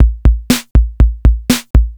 TSNRG2 Breakbeat 014.wav